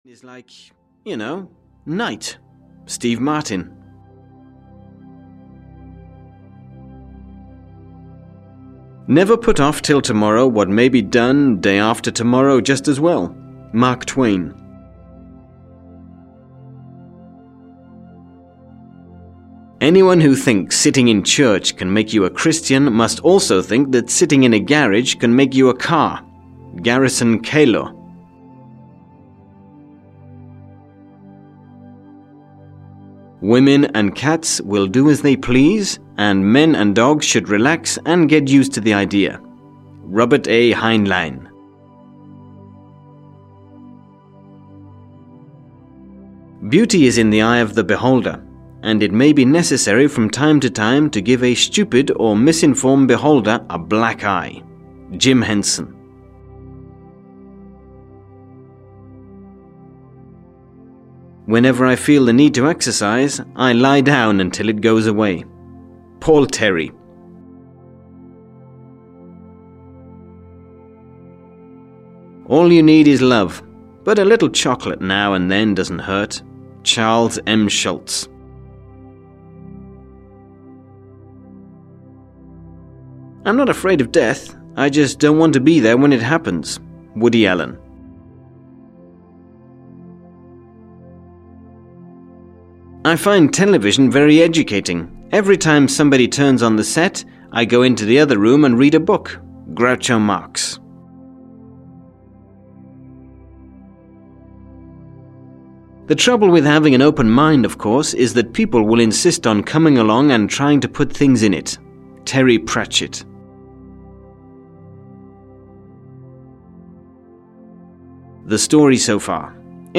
100 Humorous Quotes (EN) audiokniha
Ukázka z knihy